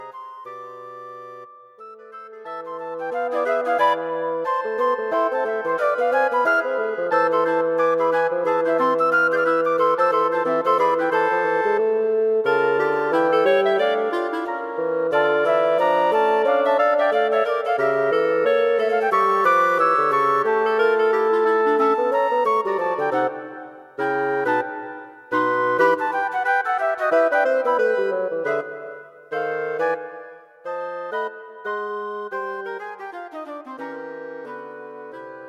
Wind Quartet for Concert performance
A fast, thorny piece.